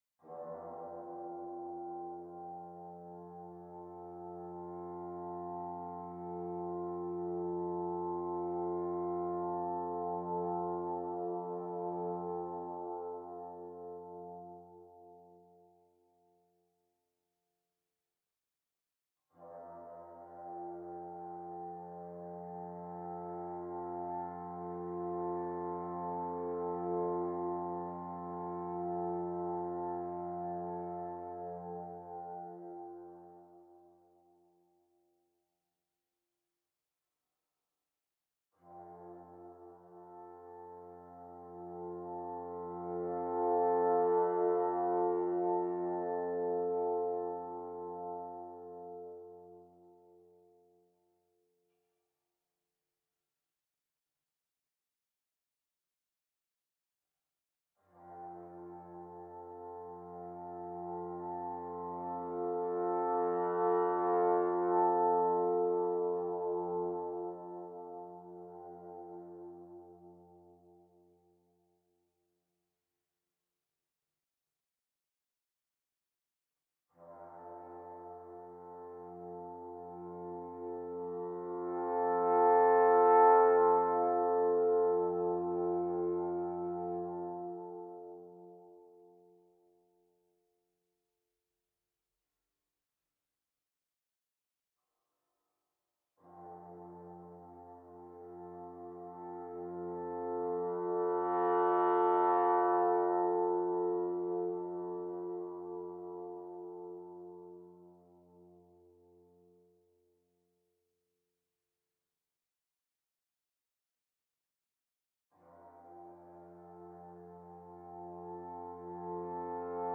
Premium bespoke royalty-free music for mediation